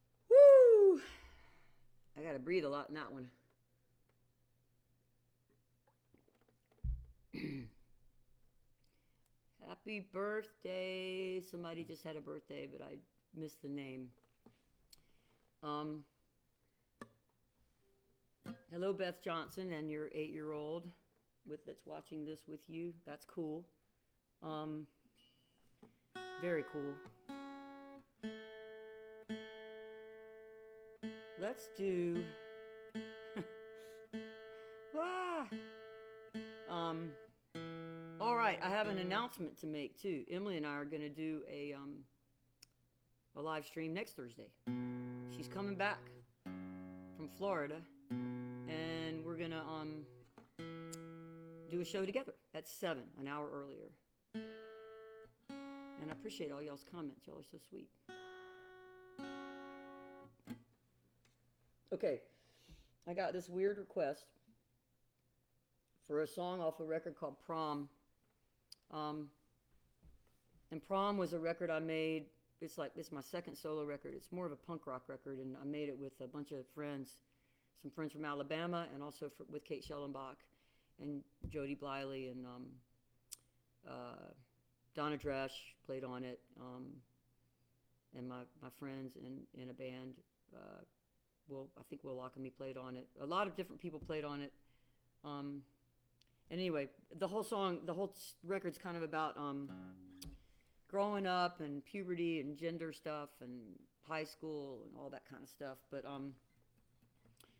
(captured from the facebook live video stream)
08. talking with the crowd (1:47)